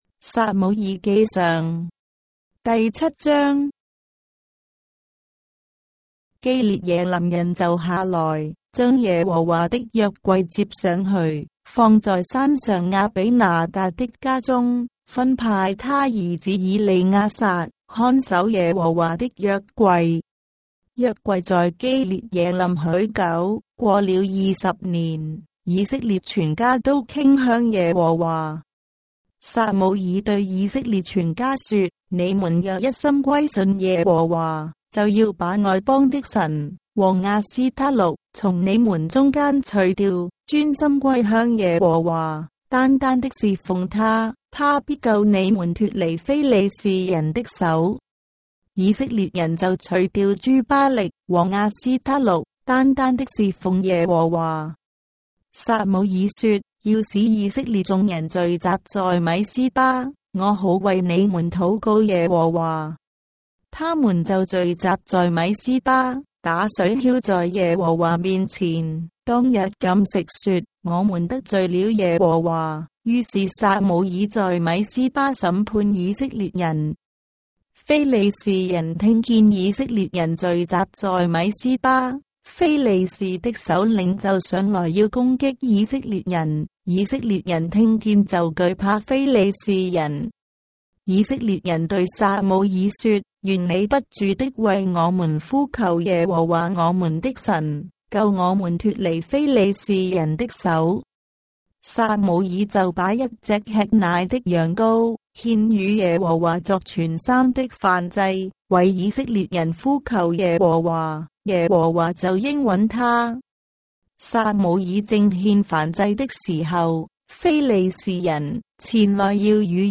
章的聖經在中國的語言，音頻旁白- 1 Samuel, chapter 7 of the Holy Bible in Traditional Chinese